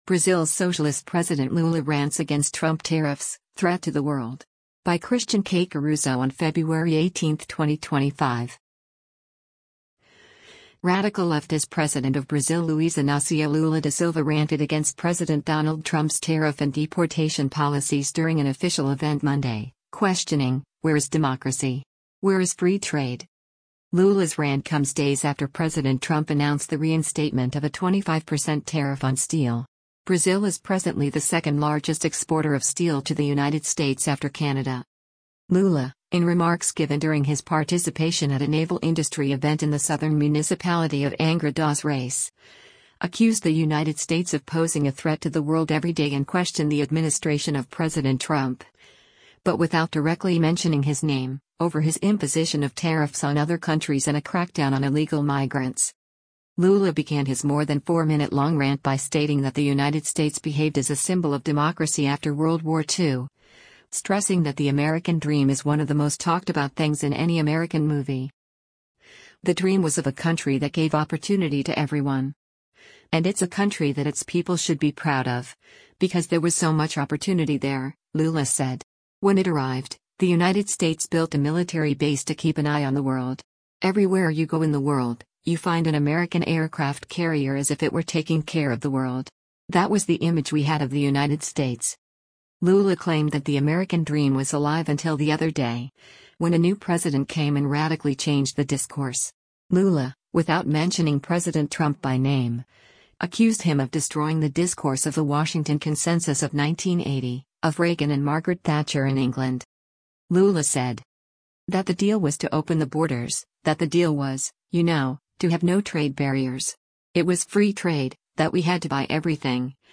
Lula, in remarks given during his participation at a naval industry event in the southern municipality of Angra dos Reis, accused the United States of posing a “threat to the world every day” and questioned the administration of President Trump — but without directly mentioning his name — over his imposition of tariffs on other countries and a crackdown on illegal migrants.